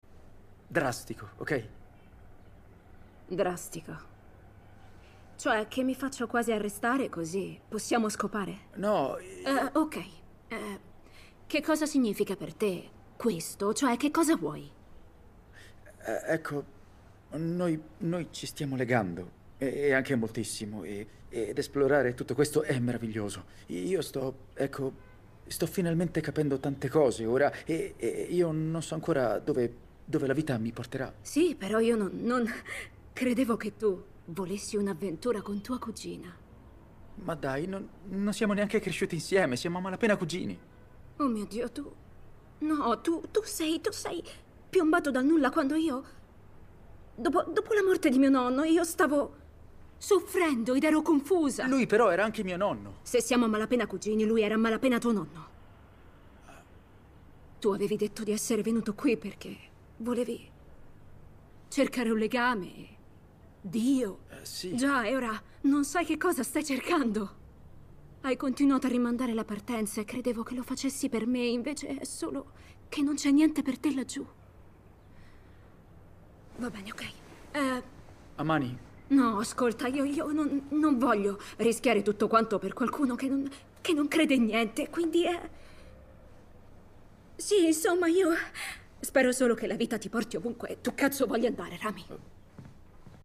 nel telefilm "Ramy", in cui doppia Rosaline Elbay.